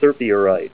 Help on Name Pronunciation: Name Pronunciation: Serpierite + Pronunciation